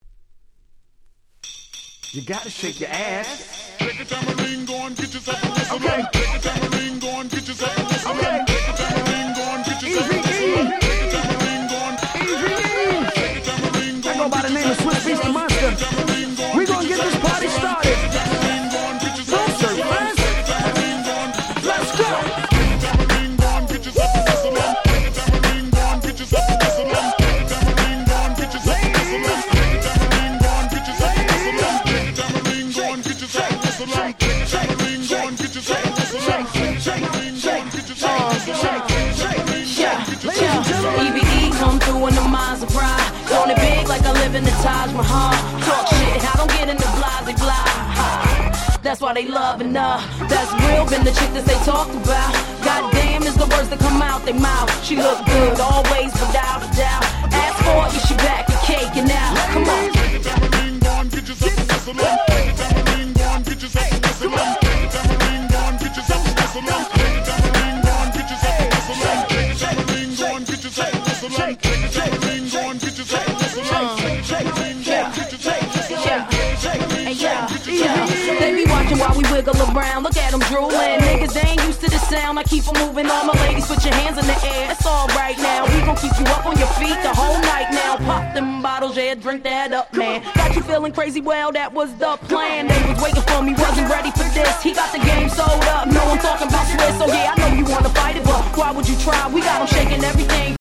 07' Big Hit Hip Hop !!
未だにうまく使えばしっかりと盛り上がる、まさに00's Party Hip Hop Classicですね！